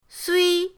sui1.mp3